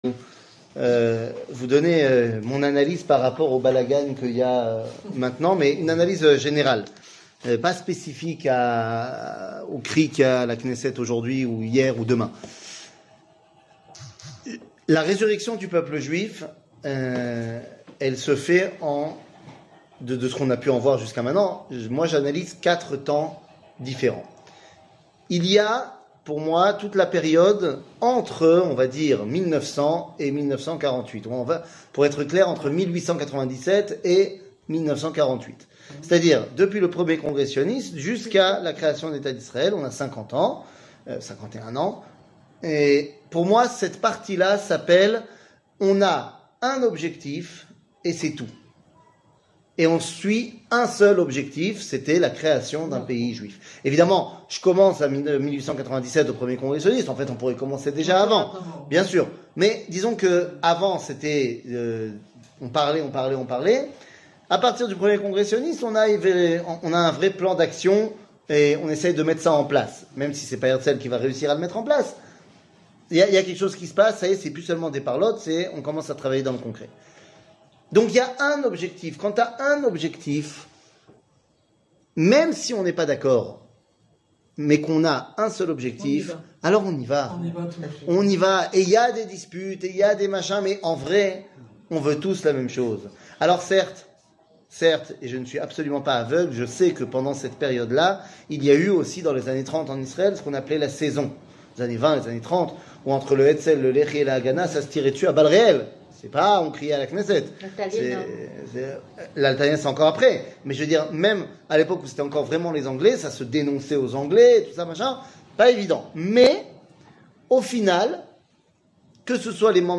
Livre de Berechit, chapitre 48, verset 21 00:46:23 Livre de Berechit, chapitre 48, verset 21 שיעור מ 15 יוני 2023 46MIN הורדה בקובץ אודיו MP3 (42.45 Mo) הורדה בקובץ וידאו MP4 (78.18 Mo) TAGS : שיעורים קצרים